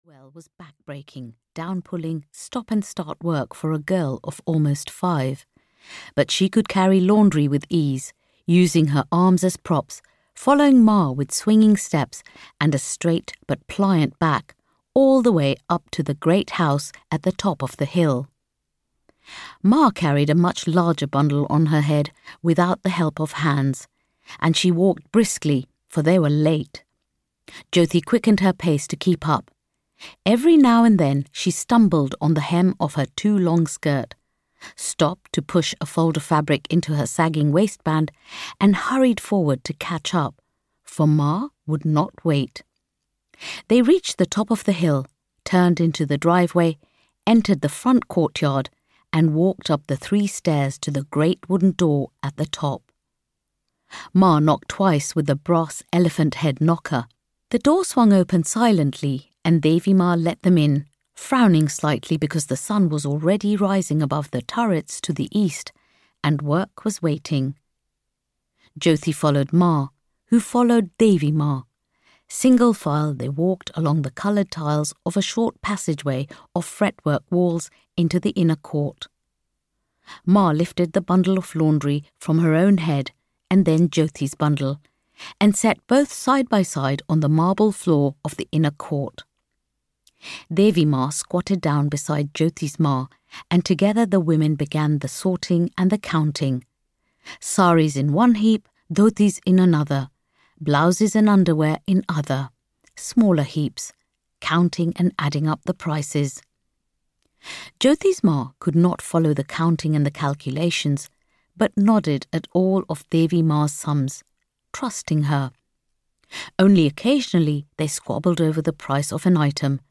The Orphan of India (EN) audiokniha
Ukázka z knihy